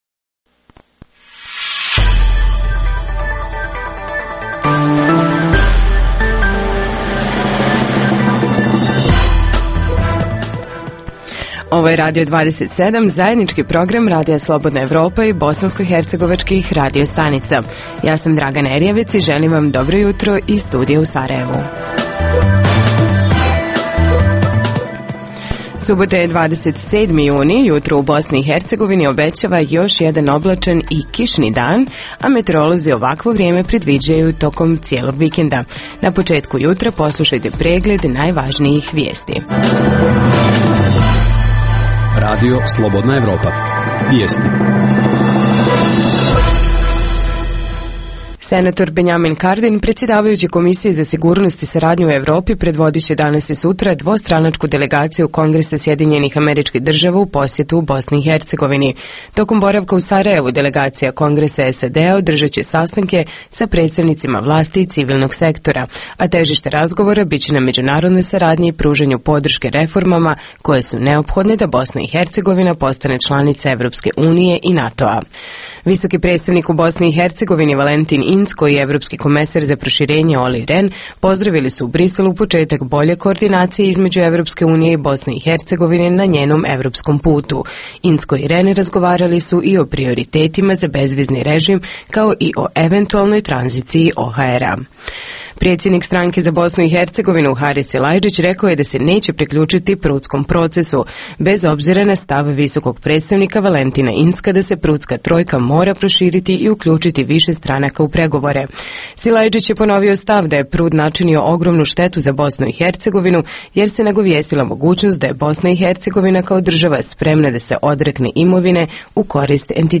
Jutarnji program za BiH koji se emituje uživo.
Tema razgovora je epidemija HIV-a/ AIDS-a u BiH Redovna rubrika Radija 27 subotom je “Estrada i show bussines”. Redovni sadržaji jutarnjeg programa za BiH su i vijesti i muzika.